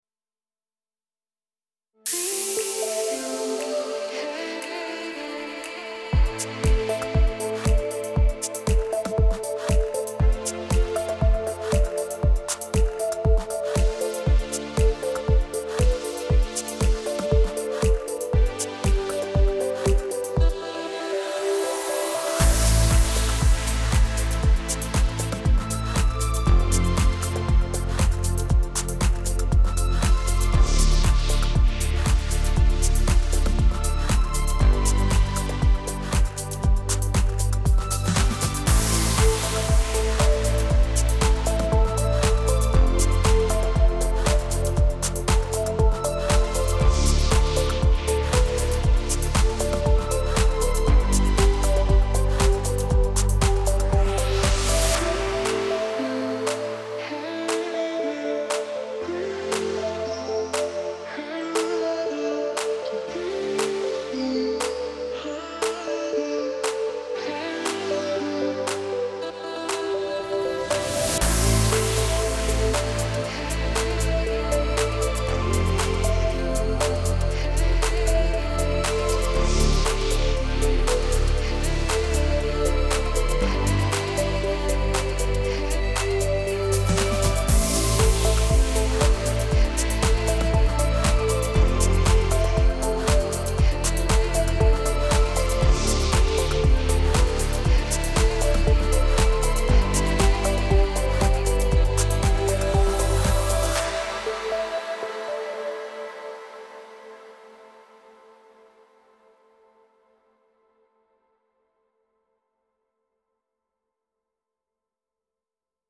Il genere EDM è sempre molto richiesto.